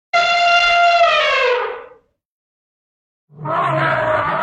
Elephant.mp3